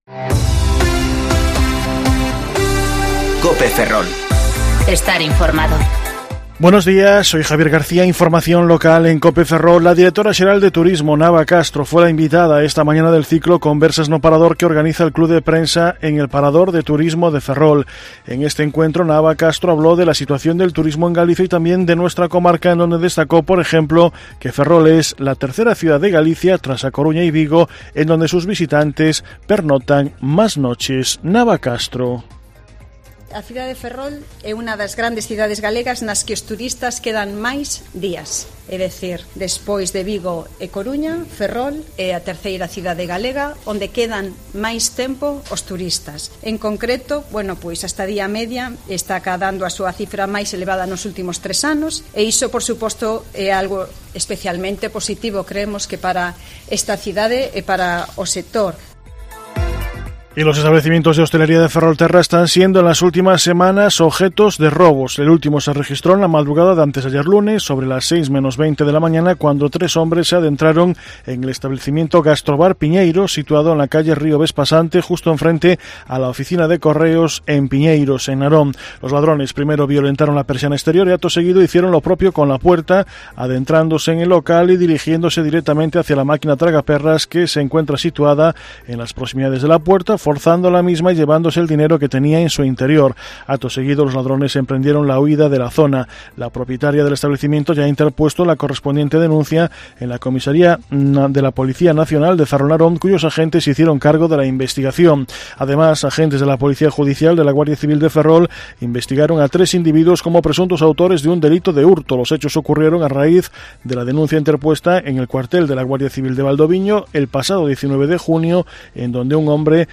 Informativo Mediodía Cope Ferrol 29/10/2019 (De 14.20 a 14.30 horas)